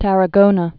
(tărə-gōnə, tärä-gōnä)